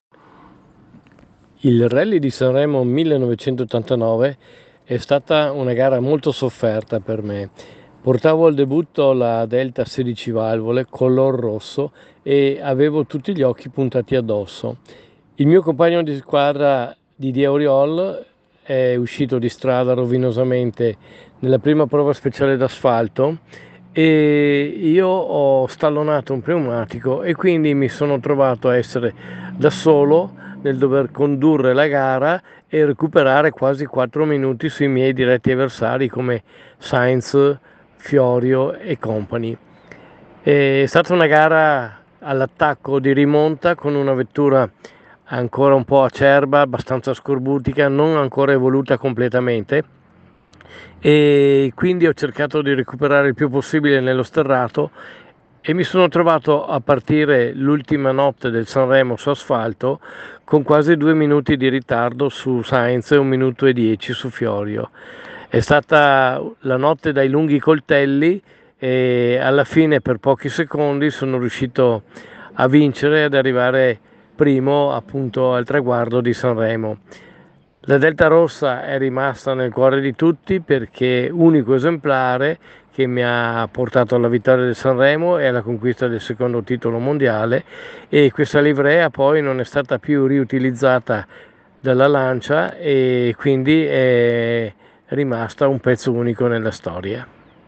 WRC | Miki Biasion ci racconta (a voce) la straordinaria vittoria al Sanremo del 1989 con la Delta Integrale 16V
È proprio lo stesso 2 volte campione del mondo Miki Biasion a raccontarci, nell’audio che trovate di seguito, quel successo ottenuto 35 anni fa. Una gara tutta in rimonta, come sentirete dallo stesso Miki, culminata nella “Notte dai lunghi coltelli” che di fatto gli diede vittoria e titolo di quel magico Rally di Sanremo del 1989.